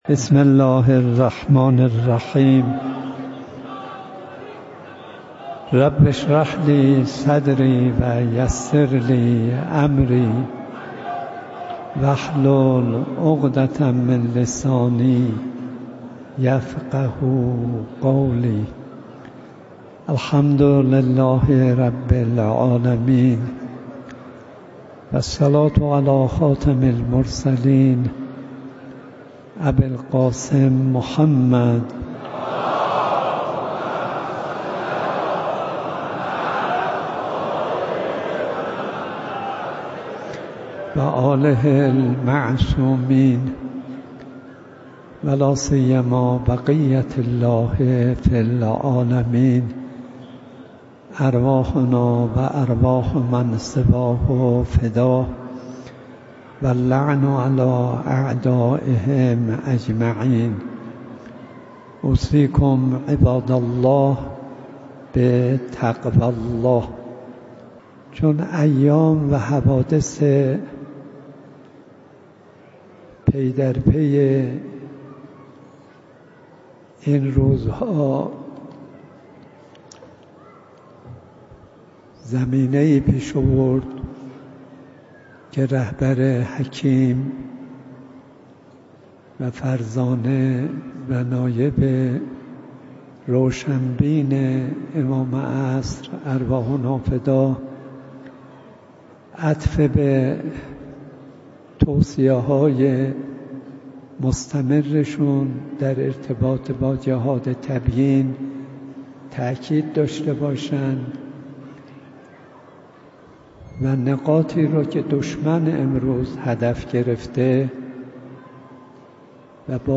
نماز عبادی ـ سیاسی جمعه تهران ـ اسفند ۱۴۰۳